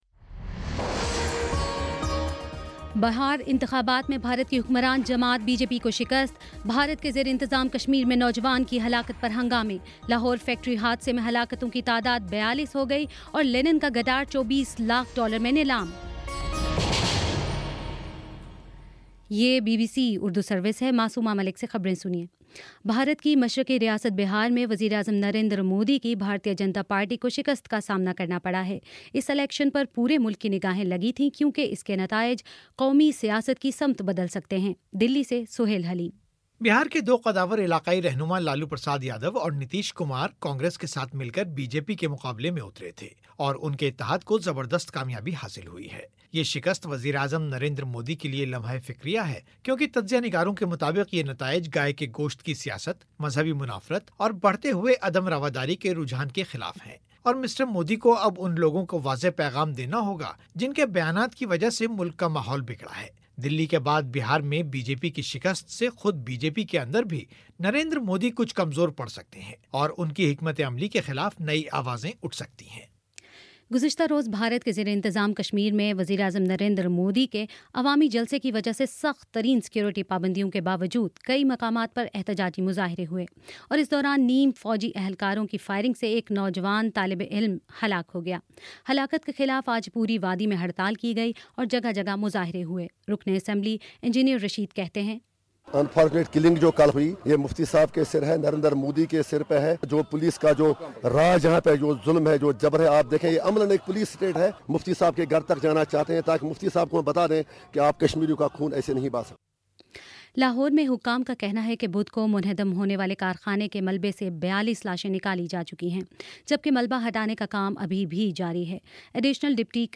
نومبر 08 : شام چھ بجے کا نیوز بُلیٹن